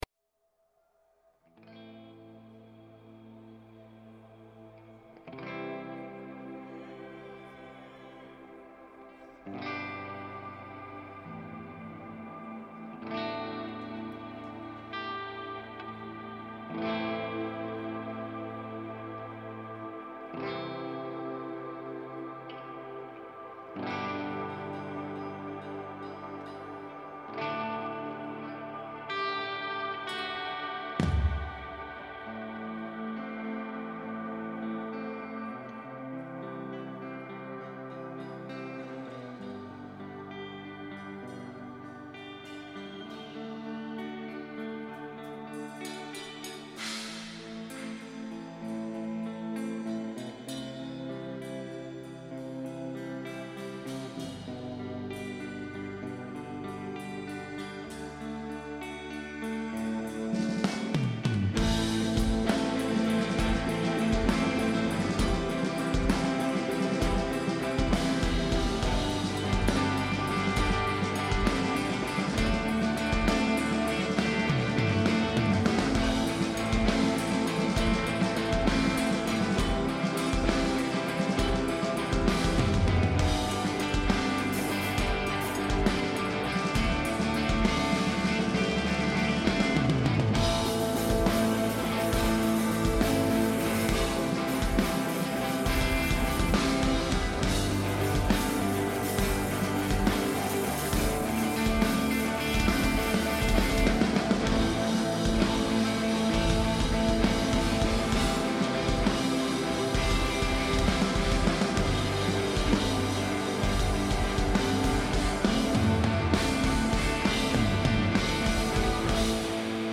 Aber weil die beiden es eben gar nicht anders können, quatschen sie mal wieder schlappe 3h am Stück - heute gibt es also die erste Hälfte der Festivalbesprechung und nächsten Sonntag dann Teil 2.
Danke an Warchrist Produktion für die Bereitstellung des Livemitschnitts von Enisum beim UTBS 2024.